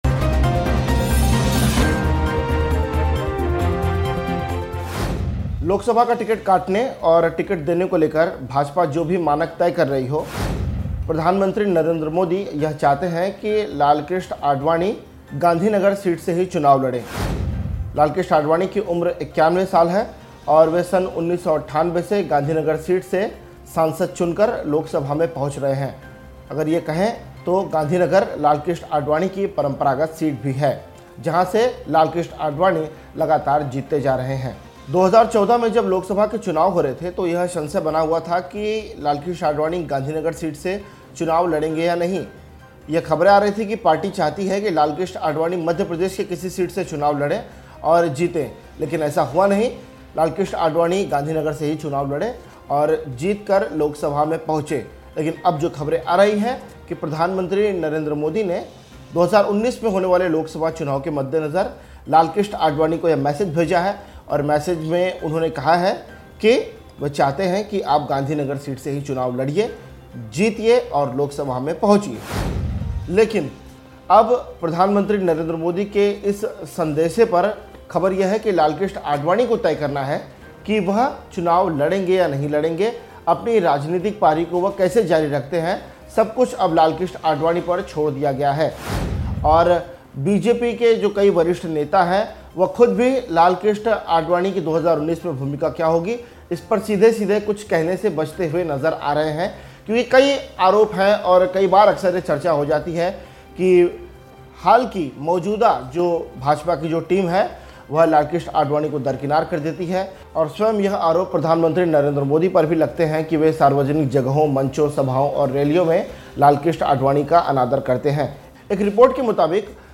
न्यूज़ रिपोर्ट - News Report Hindi / आडवाणी का नरेंद्र मोदी को झटका, लोकसभा चुनाव में इस उम्मीदवार को उतार बढ़ाएंगे बीजेपी का टेंशन